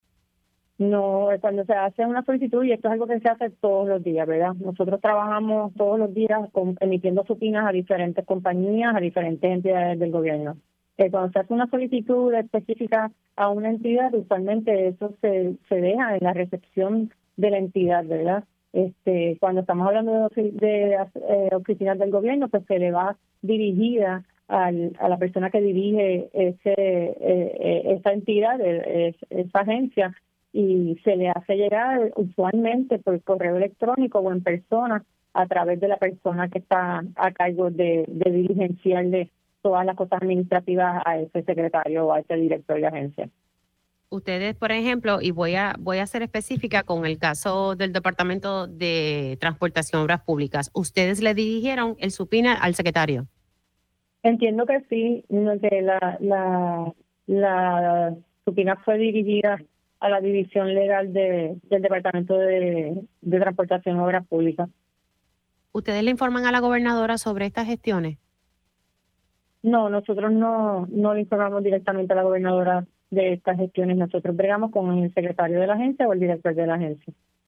La agente especial a cargo de las Investigaciones de Seguridad Nacional (HSI, por sus siglas en inglés) en Puerto Rico, Rebecca González informó en Pega’os en la Mañana que han realizado 568 detenciones en lo que va del 2025.